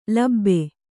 ♪ labbe